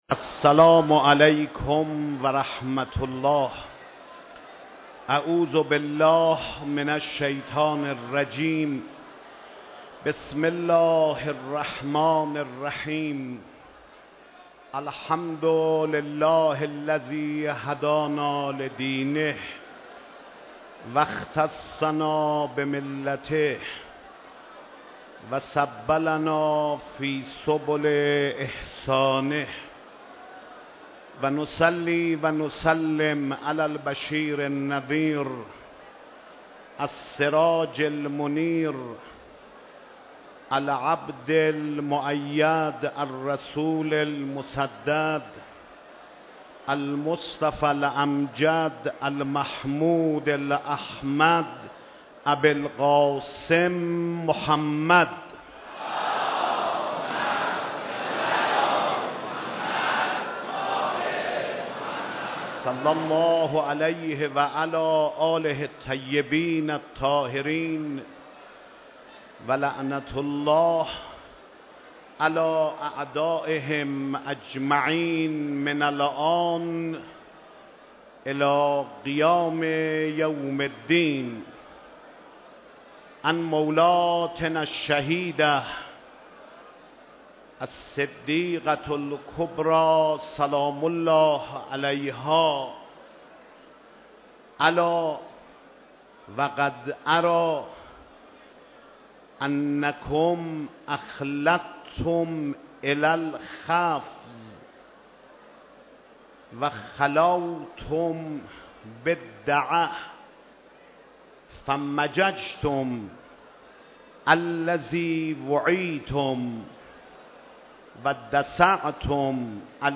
مراسم شب شهادت حضرت فاطمه زهرا سلام الله علیها در حسینیه امام خمینی (ره) برگزار شد
سخنرانی حجت الاسلام و المسلمین خاتمی